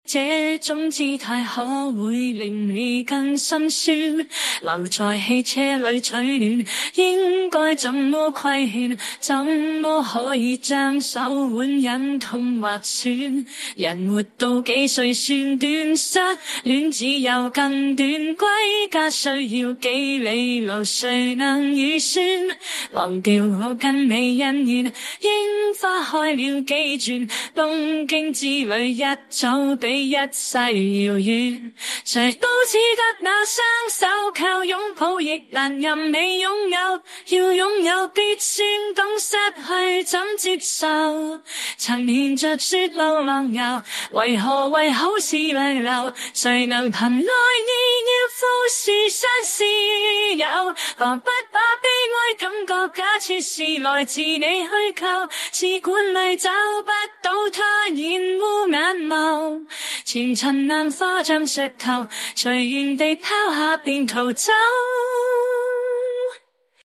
一个不够，再来一个，女生翻唱